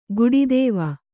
♪ guḍi dēva